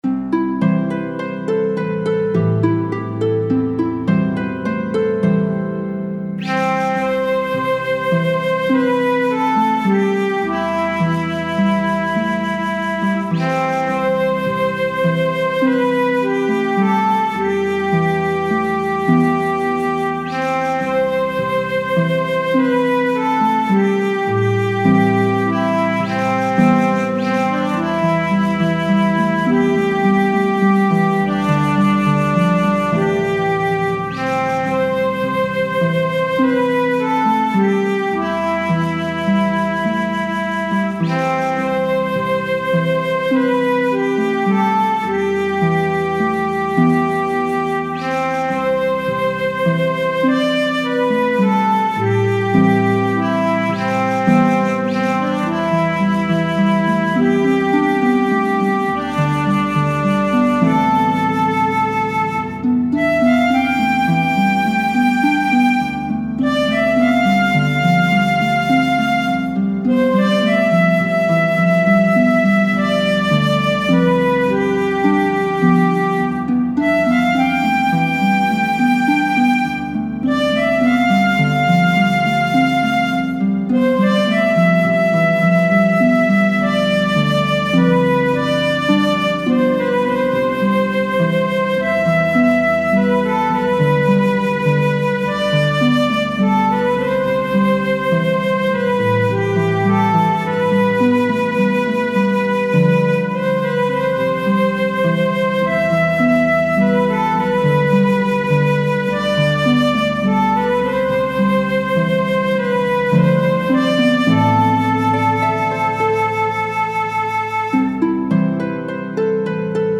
フリーBGM 街・拠点・村など のんびり・ほのぼの
フェードアウト版のmp3を、こちらのページにて無料で配布しています。